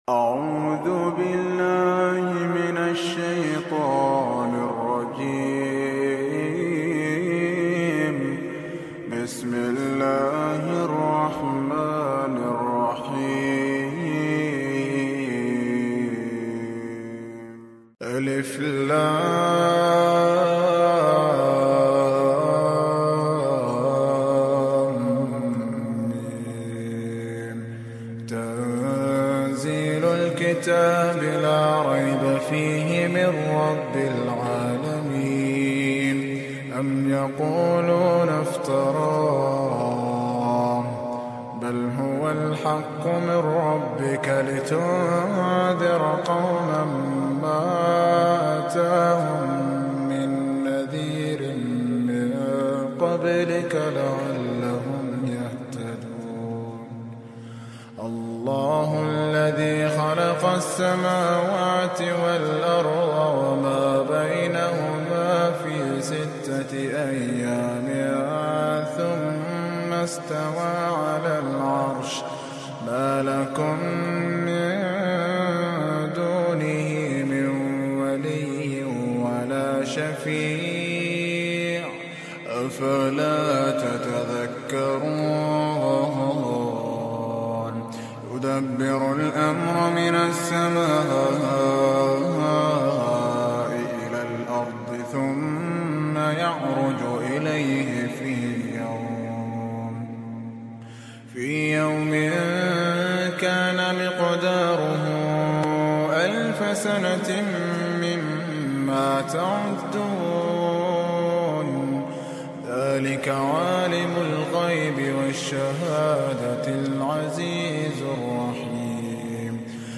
Very heart soothing recitation | Calming Quran